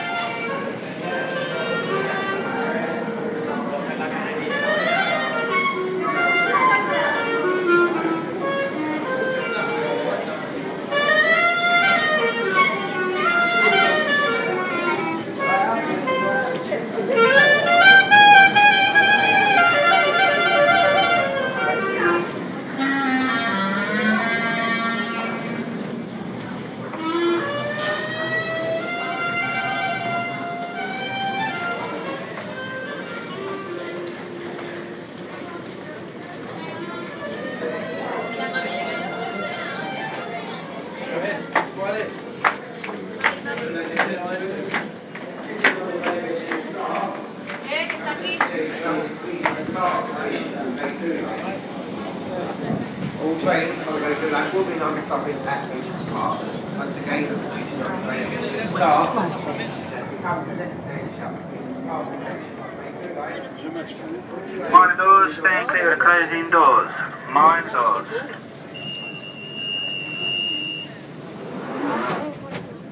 Busker on the Central Line